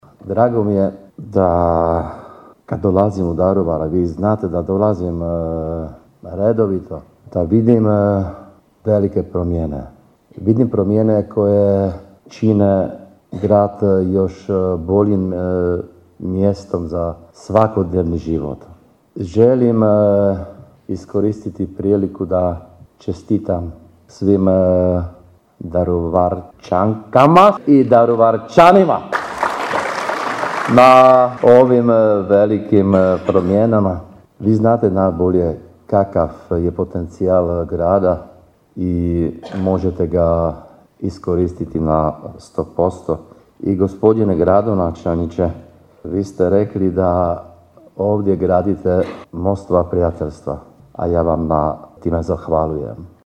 Građenje mostova, kulture dijaloga i uvažavanje suradnje bila je polazišna osnova govora veleposlanika Češke Republike u Republici Hrvatskoj. Njegova Ekselencija Milan Hovorka na odličnom hrvatskom jeziku i gromoglasnim pozdravom na češkom jeziku, zahvalio se na pozivu na svečanost čestitajući Daruvarčanima Dan Grada: